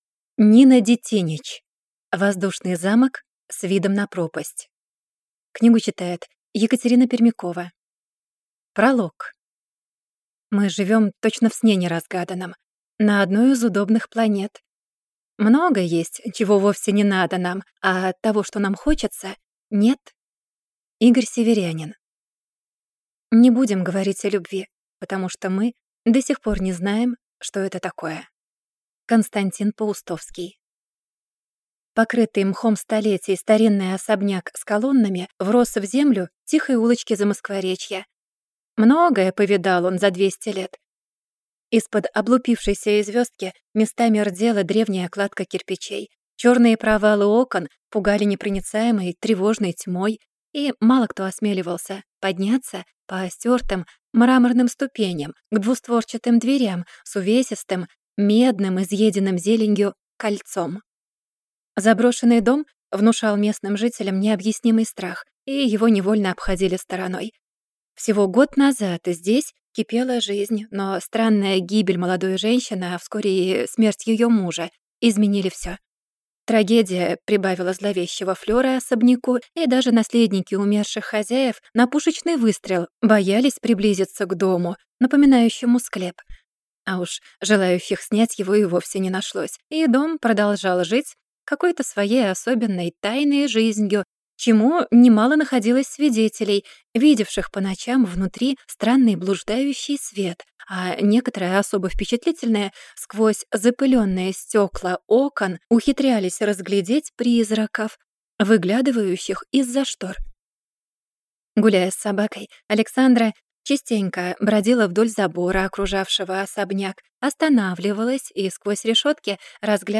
Аудиокнига Воздушный замок с видом на пропасть | Библиотека аудиокниг